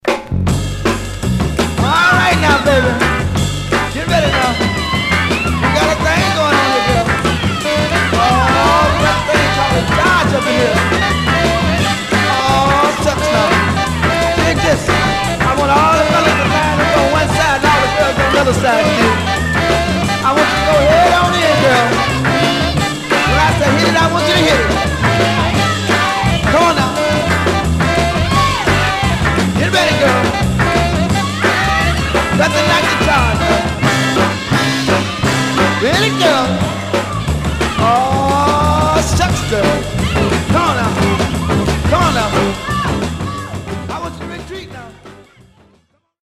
Mono
Folk